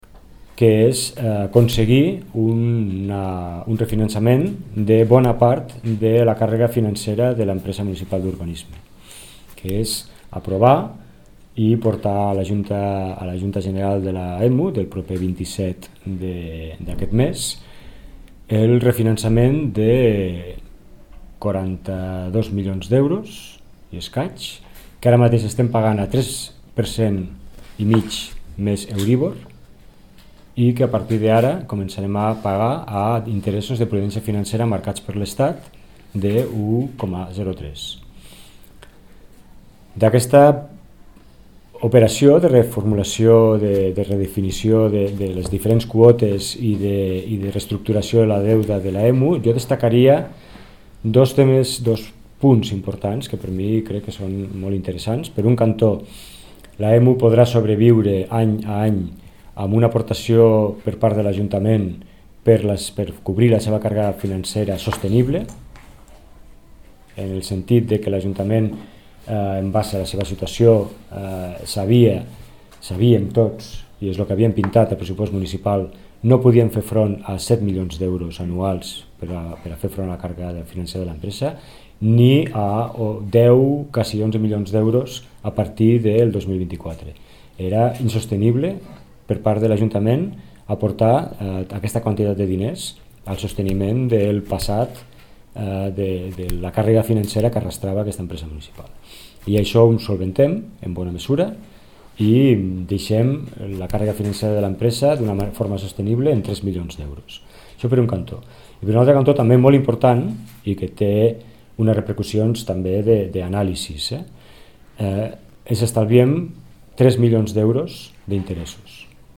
tall-de-veu-del-tinent-dalcalde-sergi-talamonte-sobre-el-consell-dadministracio-de-lemu